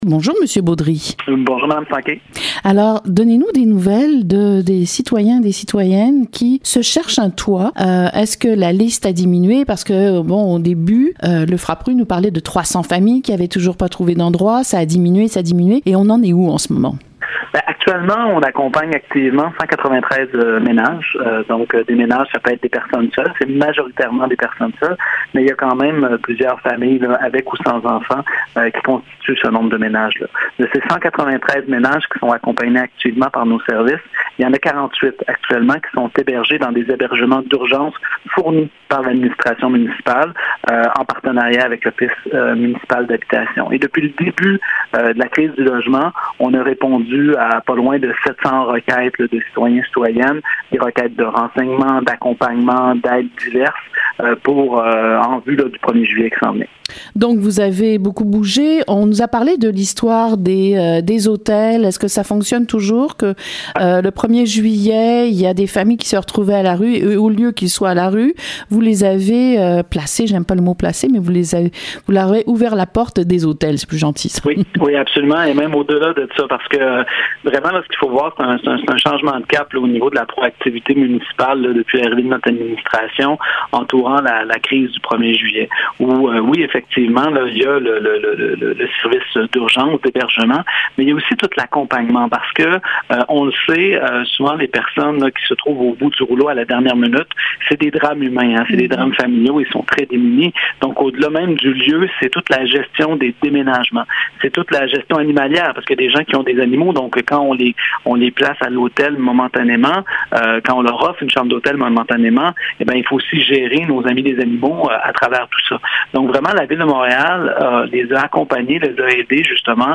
Monsieur Robert Beaudry était en entrevue au micro d’Info-Centre-Ville :